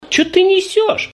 • Качество: 320, Stereo
мужской голос
голосовые